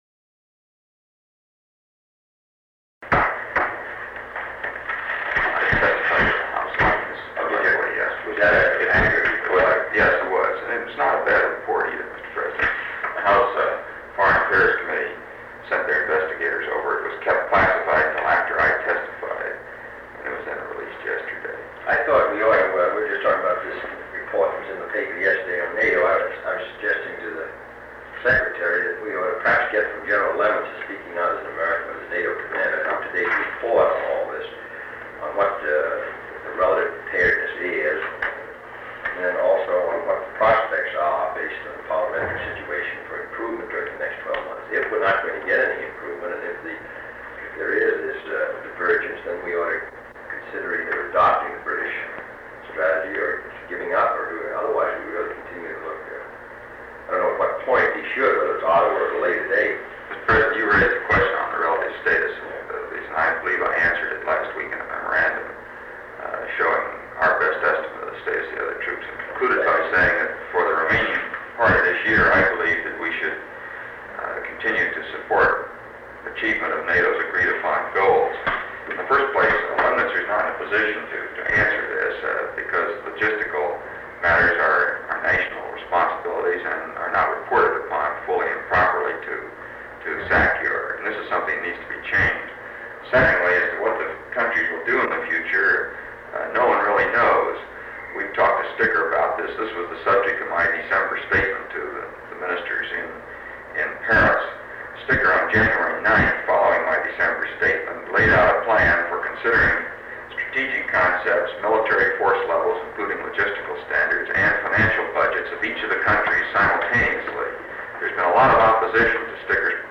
NATO, 13 May 1963 Robert S. McNamara John F. Kennedy Dean Rusk Human Rights and Civil Rights American Defense and Security Media and the Press Foreign Affairs Sound recording of a meeting between President John F. Kennedy, Secretary of Defense Robert S. McNamara, and Secretary of State Dean Rusk.
President Kennedy, Defense Secretary Robert McNamara and Secretary of State Dean Rusk discuss a House Foreign Affairs Committee report on NATO that was just partially released, as well as commentary of NATO military force levels, financial budgets of each of the countries, and the upcoming meeting in Ottawa, Canada. Cuba and the civil rights situation in Birmingham, Alabama, are also briefly mentioned. Two segments of the recording totaling 17 seconds have been removed in accordance with Section 3.4 (b) (1), (3) of Executive Order 12958.
Secret White House Tapes | John F. Kennedy Presidency Meetings: Tape 86.